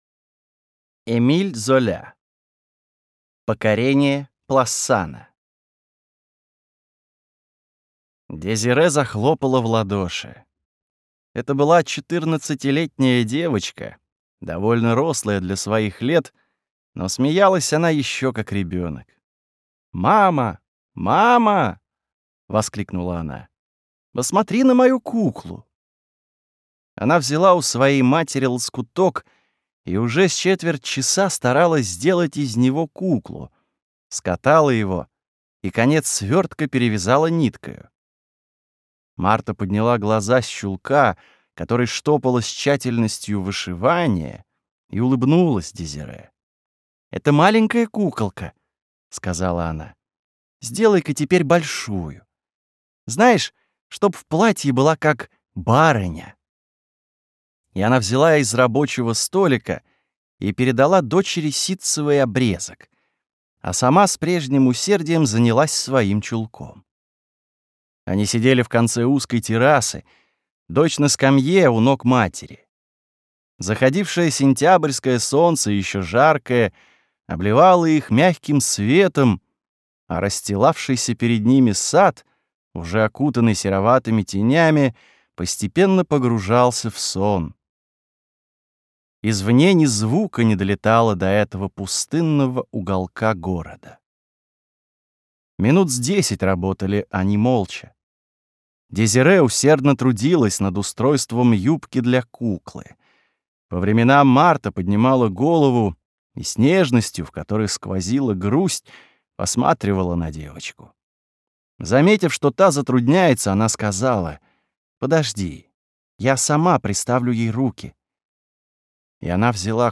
Аудиокнига Покорение Плассана | Библиотека аудиокниг